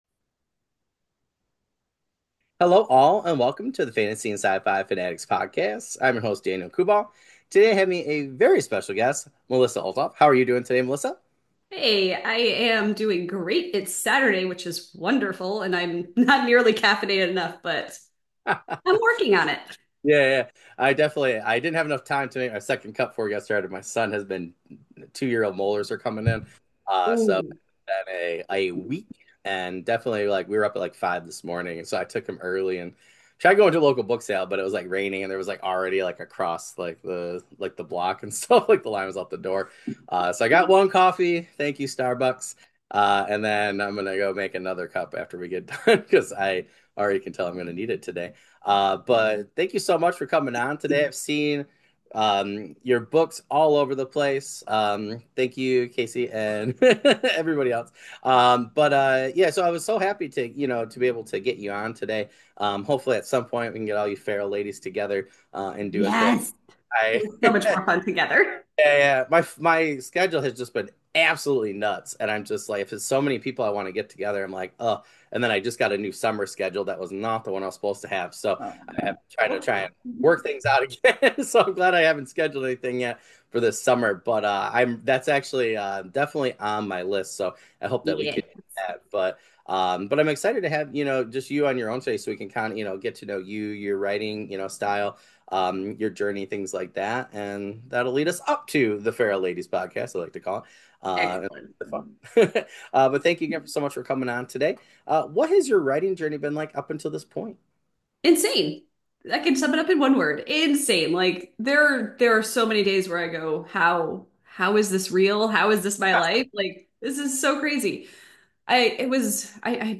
For this episode of Season 4, I had the pleasure of interview fantasy author